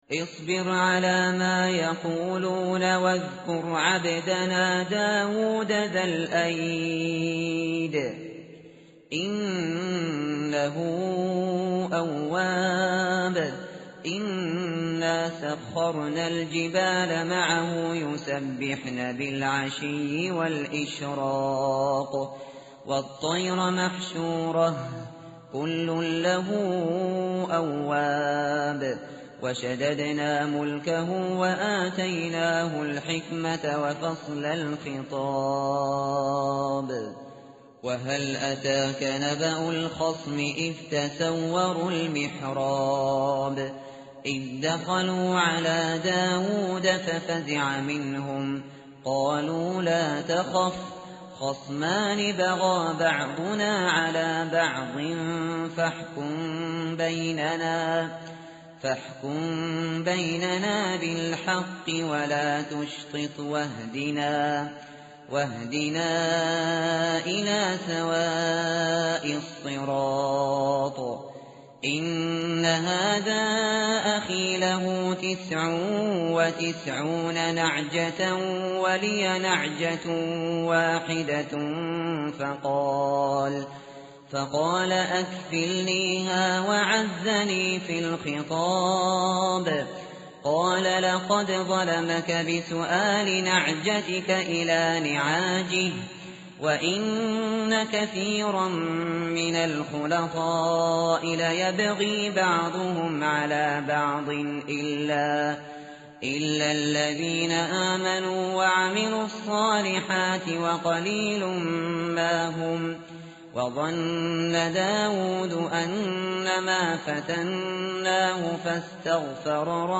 tartil_shateri_page_454.mp3